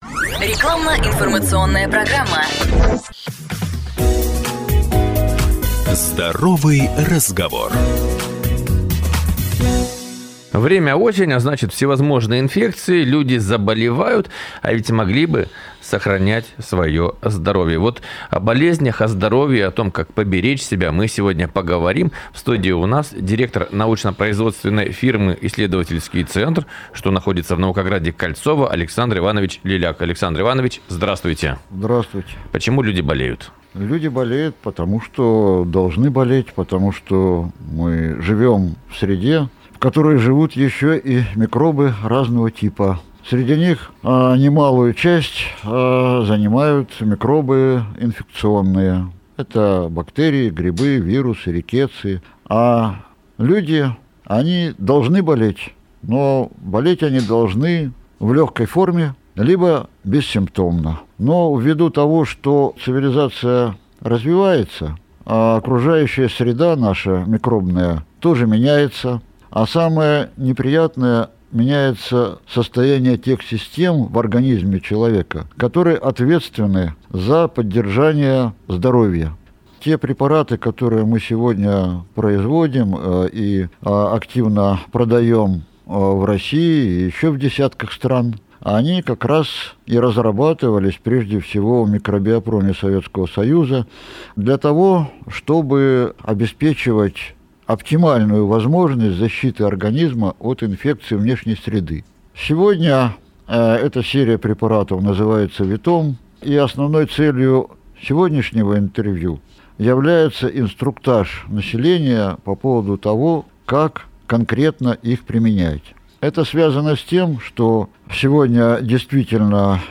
интервью